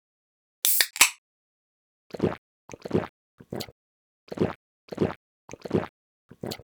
drink_can1.ogg